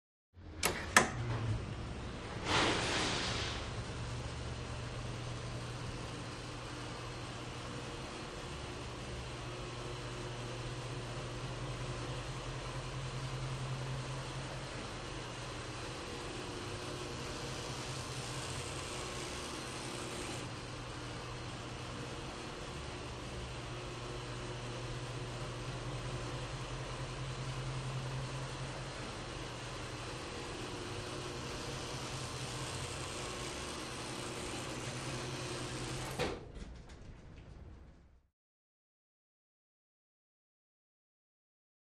Elevator - Modern, Up & Down Movement